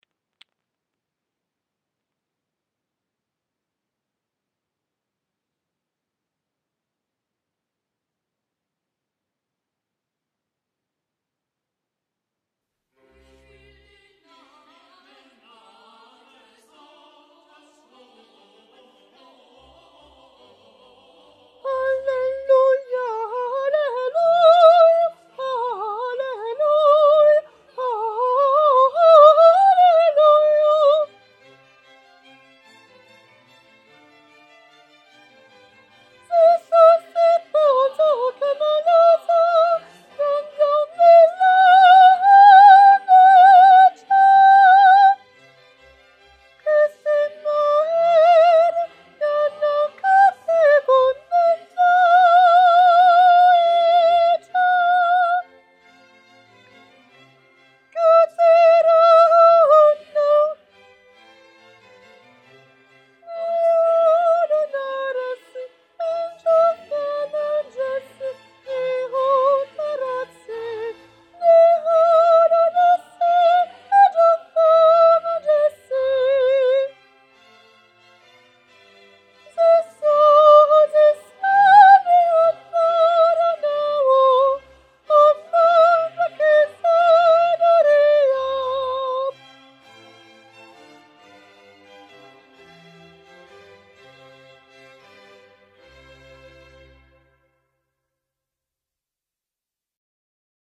cantate_142_soprano-23_04_2014-21-35.mp3